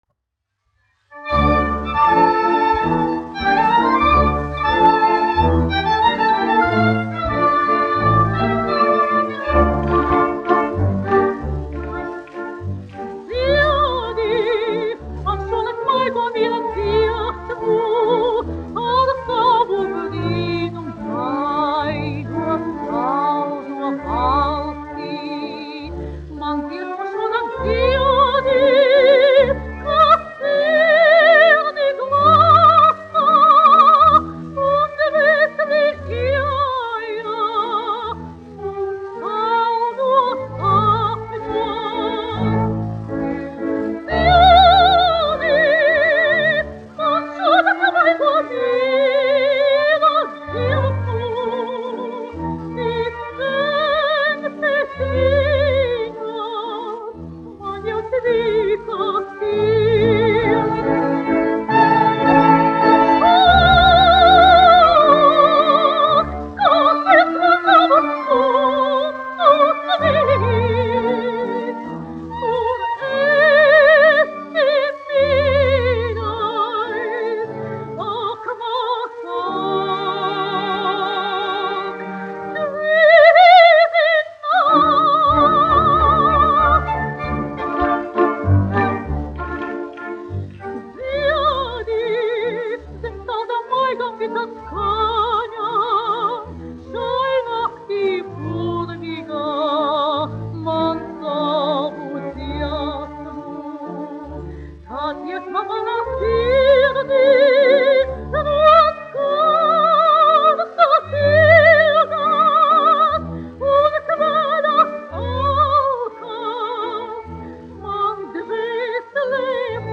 1 skpl. : analogs, 78 apgr/min, mono ; 25 cm
Populārā mūzika -- Itālija
Dziesmas (vidēja balss)
Skaņuplate